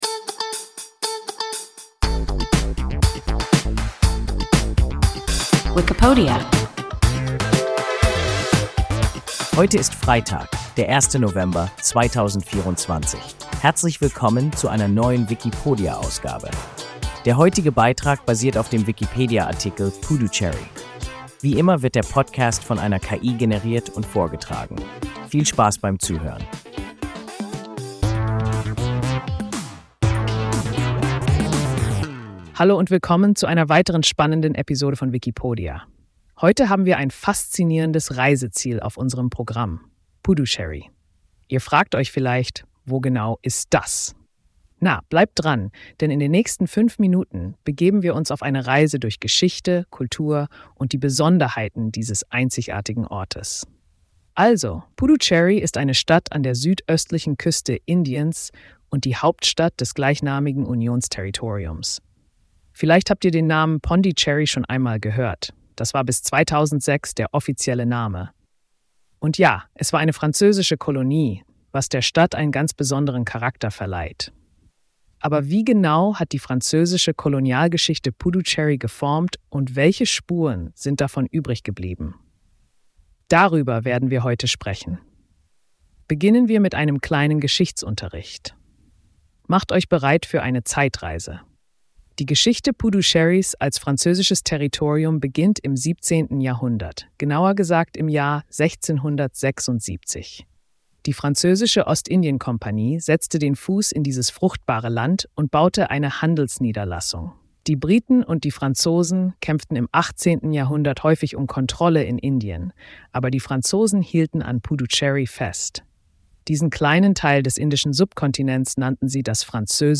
Puducherry – WIKIPODIA – ein KI Podcast